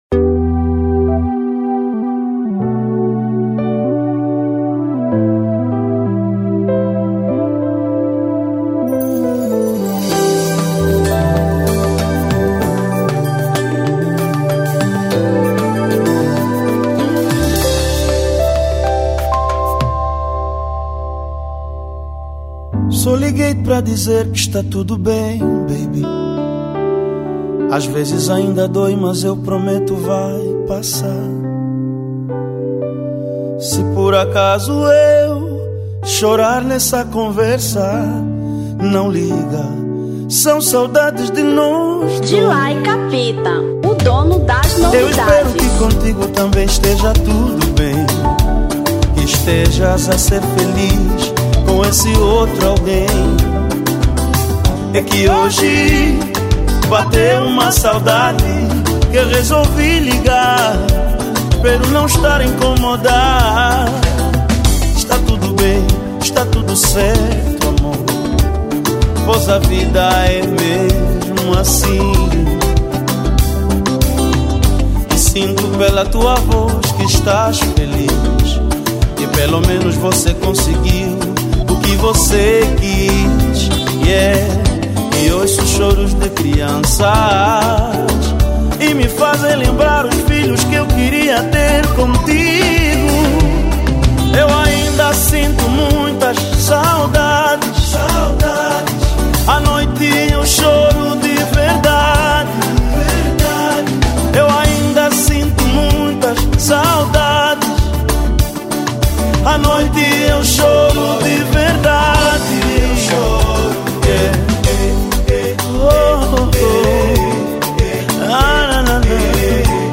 Kizomba 2005